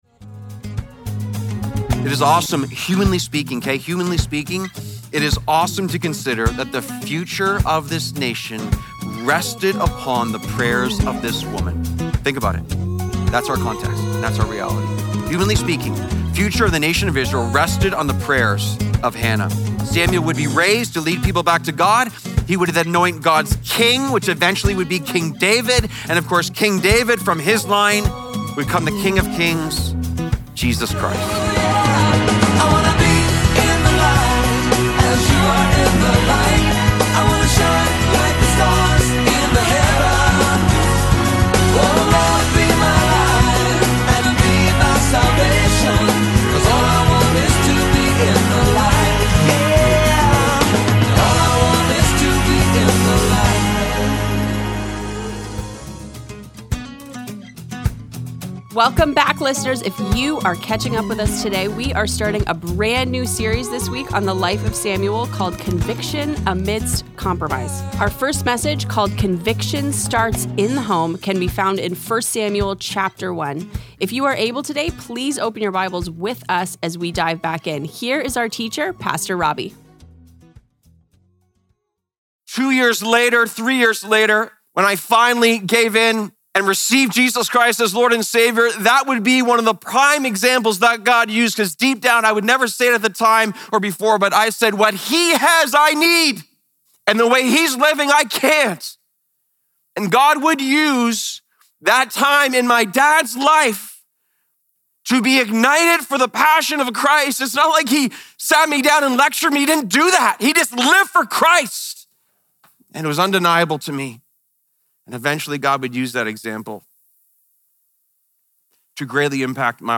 Live in the Light Daily Broadcast